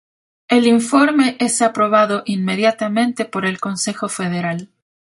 in‧for‧me
/inˈfoɾme/